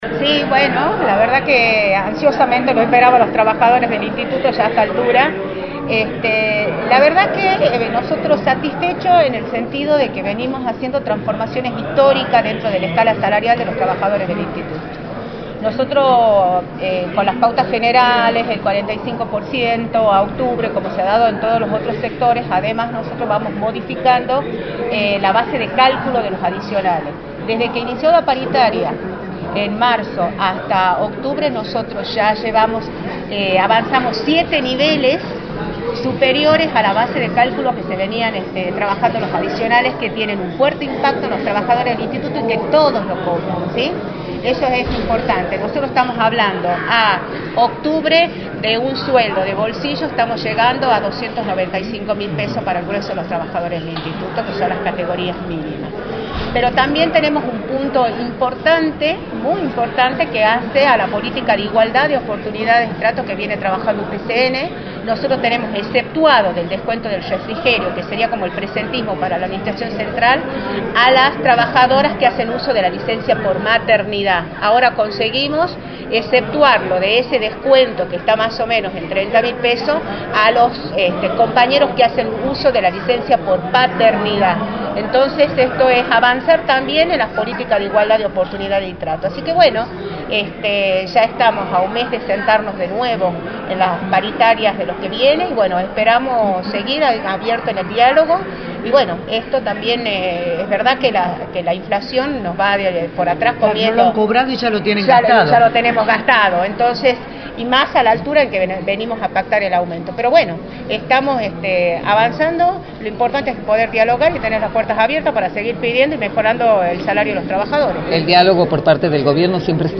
analizó en Radio del Plata Tucumán, por la 93.9, los resultados del acuerdo paritario con el gobierno.
en entrevista para «La Mañana del Plata», por la 93.9.